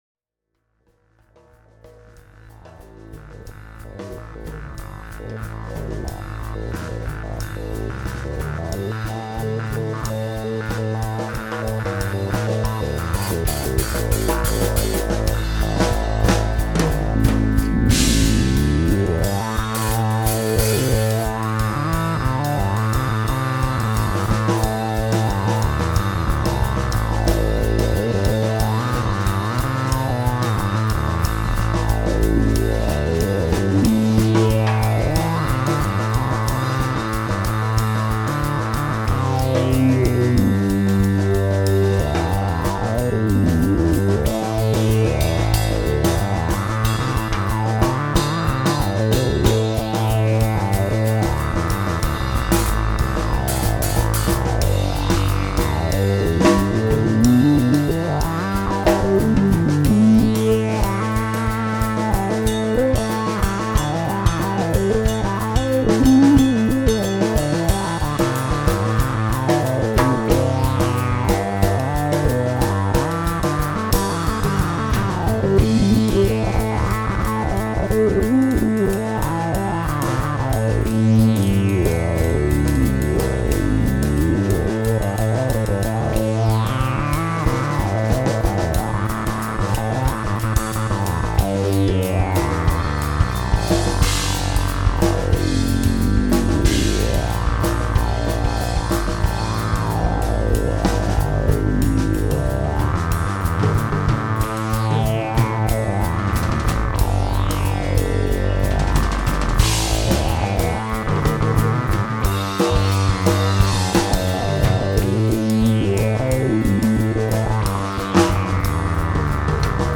So here's a track that I think is pretty representative of one of my approaches to lead bass. Since I've been controlling my Little Phatty with MIDI bass pedals, I can't control the frequency of my low-pass filter, so I've been using the smoothed-out sample-and-hold from the CP-251. Actually, I used the regular S&H at the beginning of the jam, but the recording engineer wasn't quick enough hitting the record button, so you only hear a little bit of it. Anyway, here it is: terminous Same set-up as some of my other posts: Gibson Melody Maker bass --> Z.Vex Woolly Mammoth fuzz --> MF-101 --> Alembic F-2B preamp I did have an expression pedal plugged into the LFO of the CP-251 so that I could subtly (or not subtly) adjust the speed at which the S&H would change.